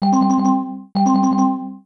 announcement.wav